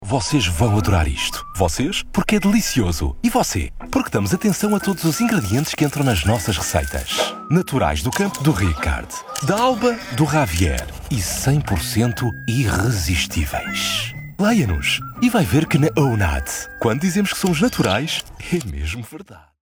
The Portuguese voiceover, friendly and impactful!
Online commercials